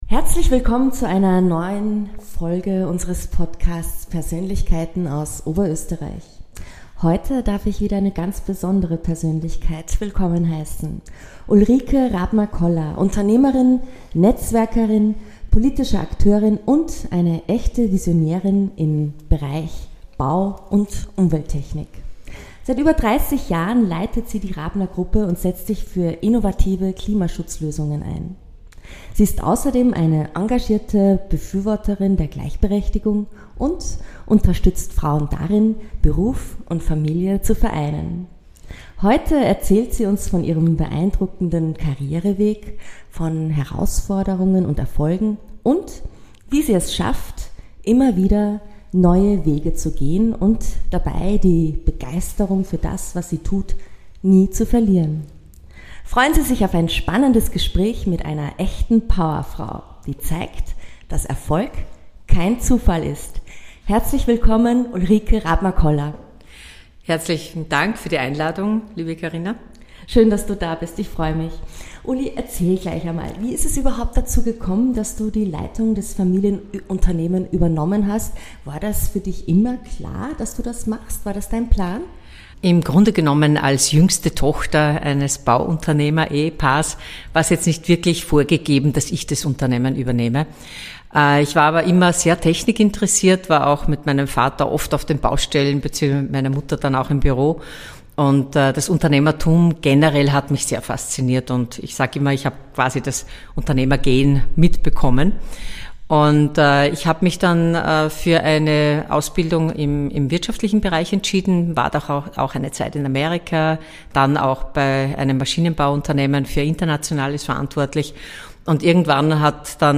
Ein besonders inspirierender Teil des Gesprächs ist ihr Engagement für die Förderung von Frauen in technischen Berufen und Führungspositionen. Erlebt eine inspirierende und energiegeladene Diskussion, die zeigt, dass nachhaltiger Erfolg aus Leidenschaft, harter Arbeit und einem klaren Blick für die Zukunft entsteht.